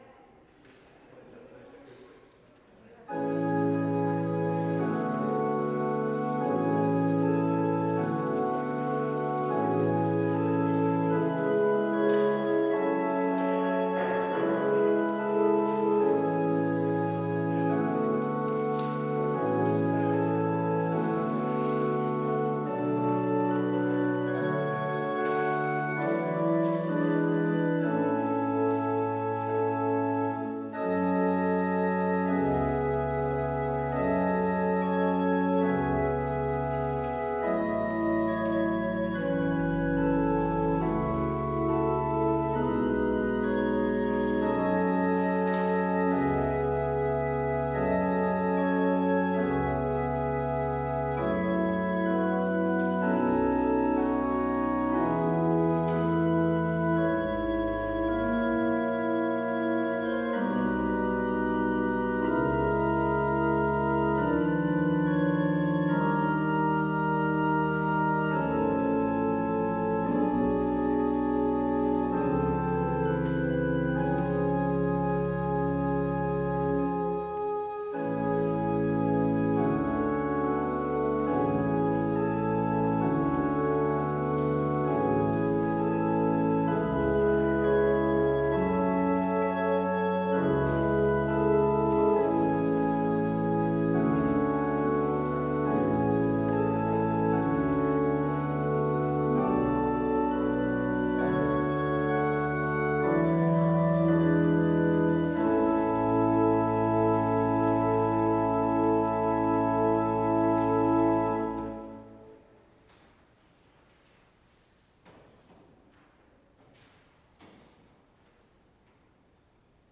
Complete service audio for Chapel - May 4, 2021